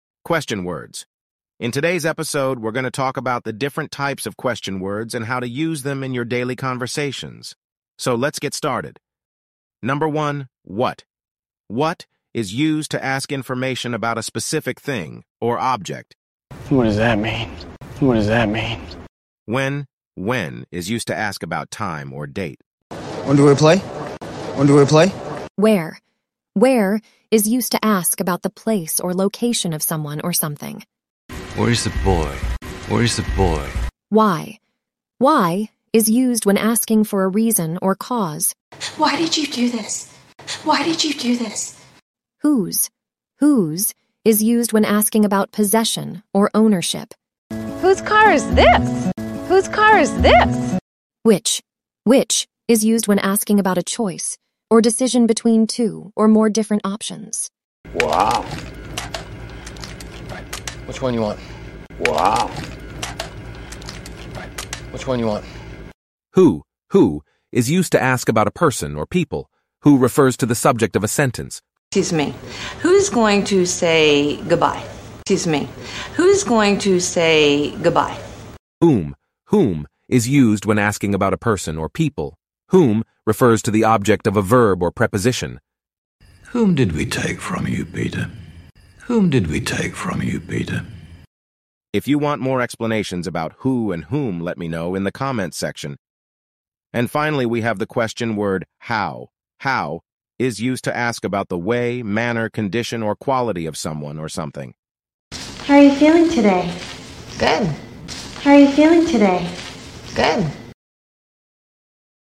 English grammar lesson: question words